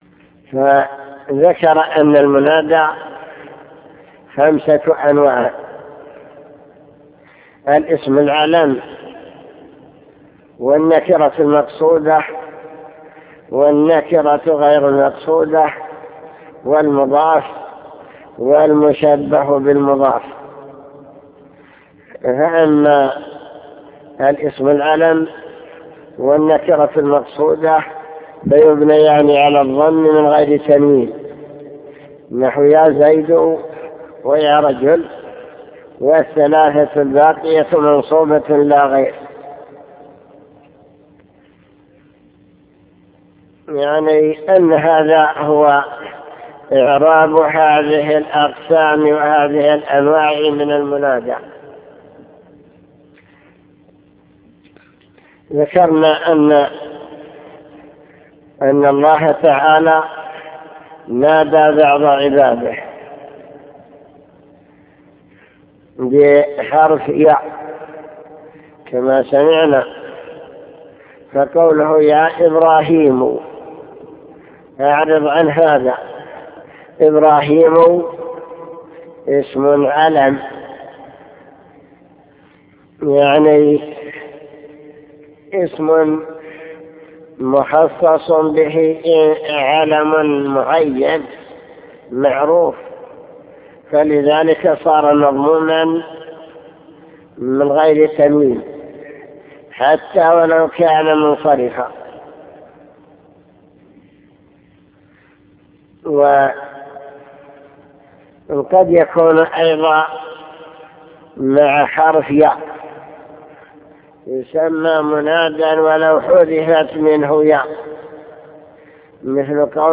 المكتبة الصوتية  تسجيلات - كتب  شرح كتاب الآجرومية المنصوبات  المنادى